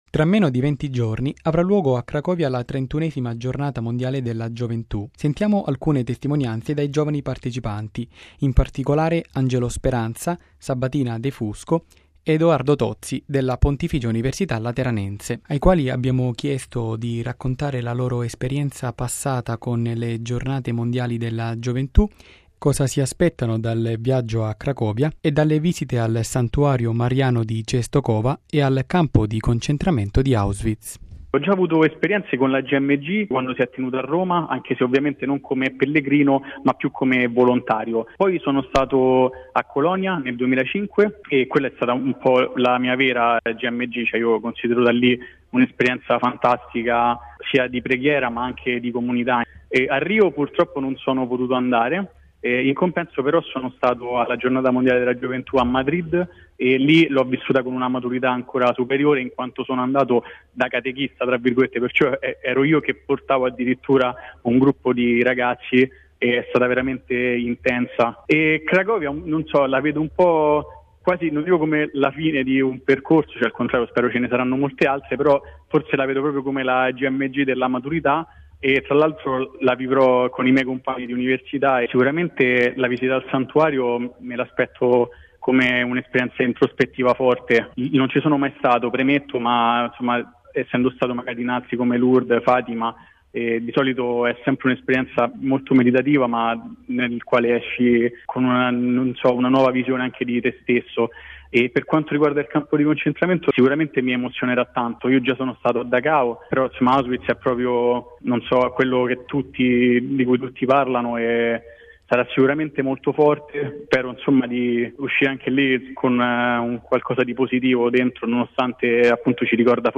Verso la Gmg: le testimonianze di tre studenti della Lateranense